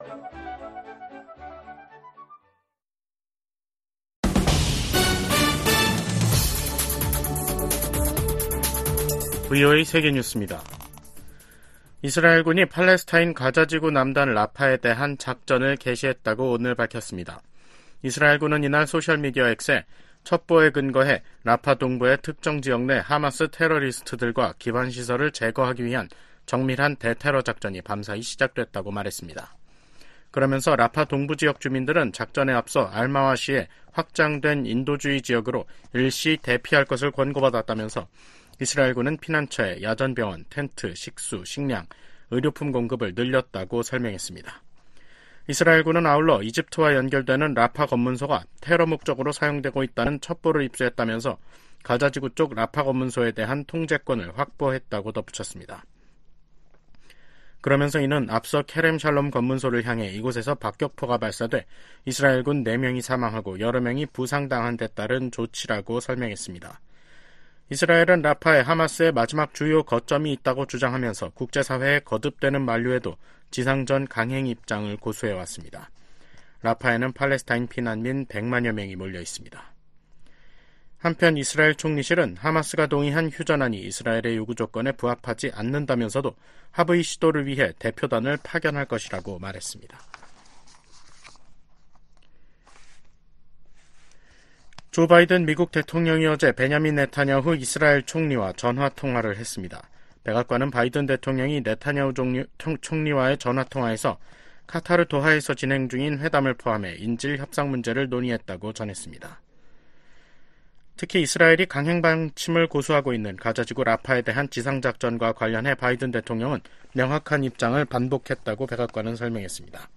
세계 뉴스와 함께 미국의 모든 것을 소개하는 '생방송 여기는 워싱턴입니다', 2024년 5월 7일 저녁 방송입니다. '지구촌 오늘'에서는 이스라엘군이 팔레스타인 가자지구 내 라파 국경검문소를 장악한 소식 전해드리고, '아메리카 나우'에서는 ‘성추문 입막음’ 의혹으로 형사 재판을 받고 있는 도널드 트럼프 전 대통령이 판사의 함구령을 또 위반해 벌금을 부과받은 이야기 살펴보겠습니다.